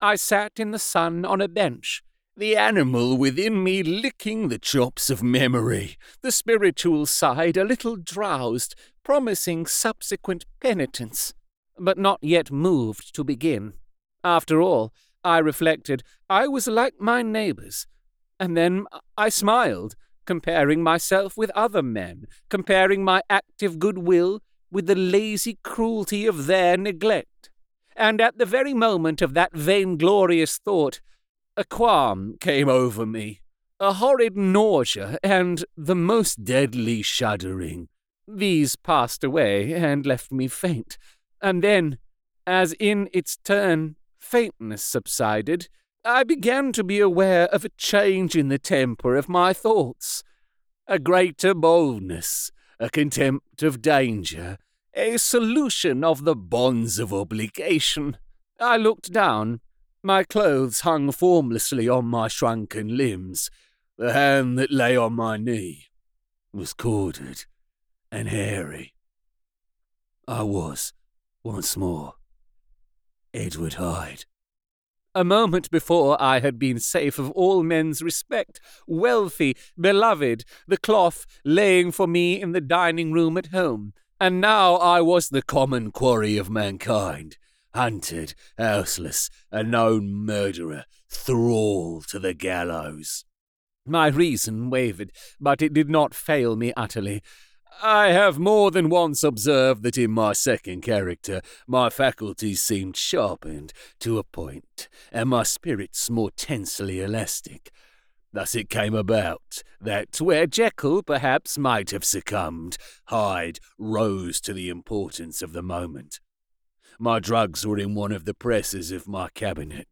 Strange-Case-of-Dr-Jekyll-and-Mr-Hyde-Retail-Sample-Audiobook-Empire-Version.mp3